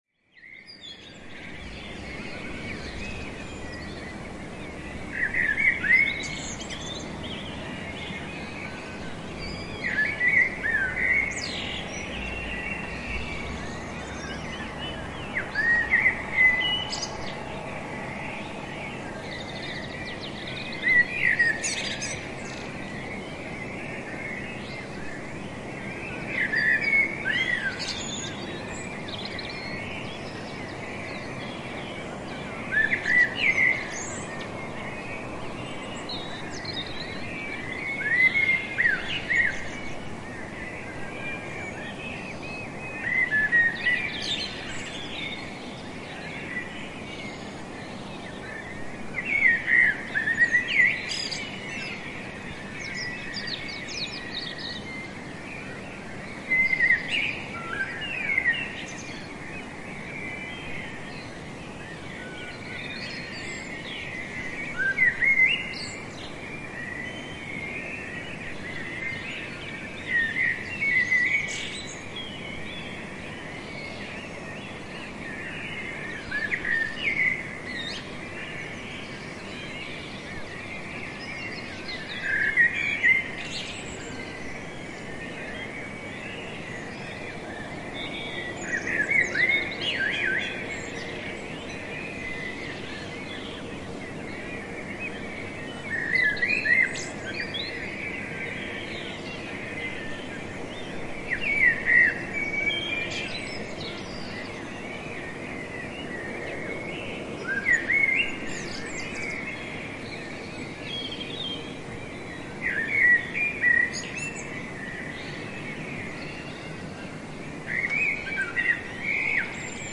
描述：在后院大雨后记录，背景中有些交通。甘蔗蟾蜍呼叫。设备Rode Podcaster到MacBook Air录制的Audacity，声音是因为它未经调整
Tag: 甘蔗蟾蜍 现场录音 城市 环境